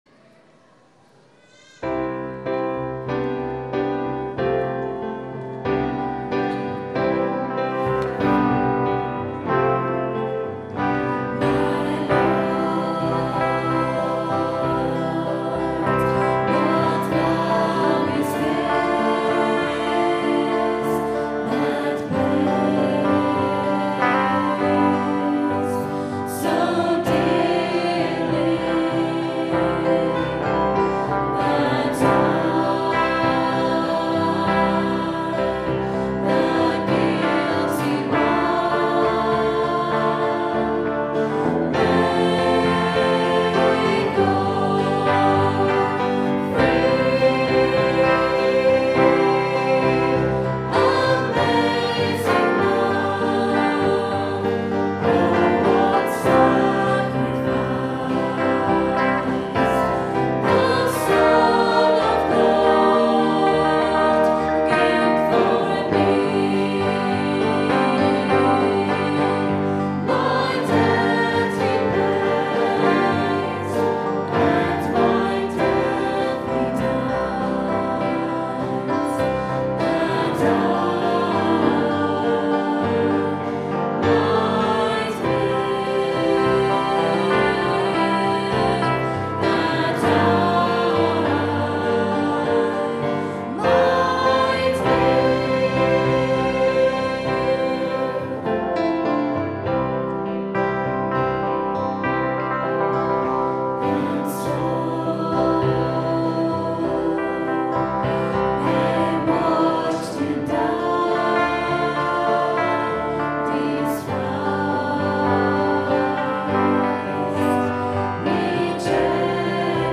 Recorded on a Zoom H4 digital stereo recorder at 10am Mass Sunday 26th September 2010.